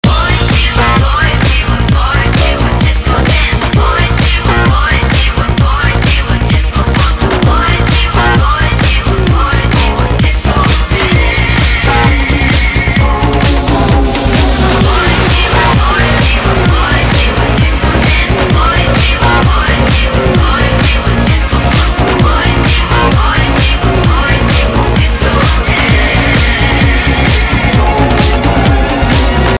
wojtyladiscohouse.mp3